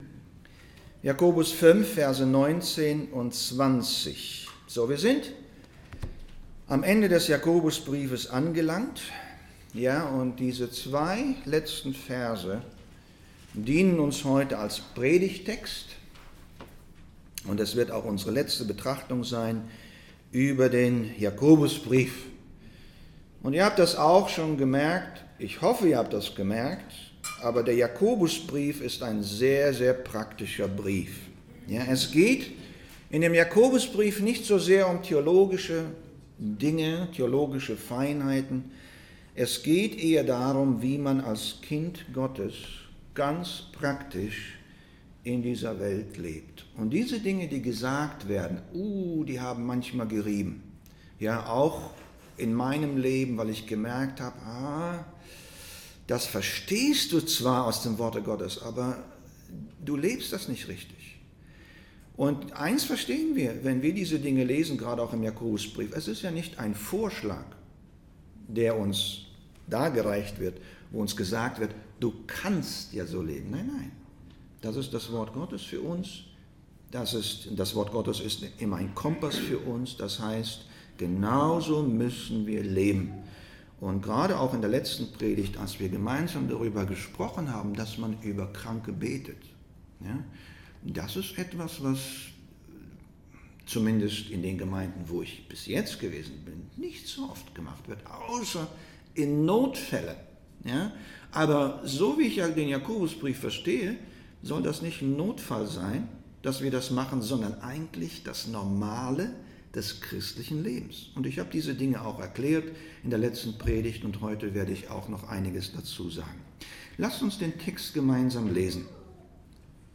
Download Download Reference Jakobus 5:19-20 From this series Current Sermon Meines Bruders Hueter?